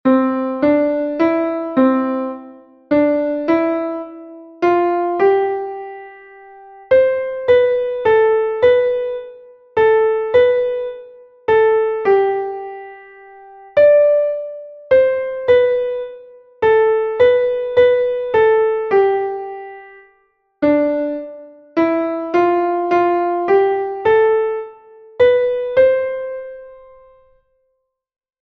Here there are four 6/8 time signature exercises.